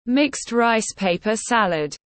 Bánh tráng trộn tiếng anh gọi là mixed rice paper salad, phiên âm tiếng anh đọc là /mɪkst raɪs ˈpeɪpə ˈsæləd/
Mixed rice paper salad /mɪkst raɪs ˈpeɪpə ˈsæləd/